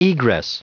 Prononciation du mot egress en anglais (fichier audio)
Prononciation du mot : egress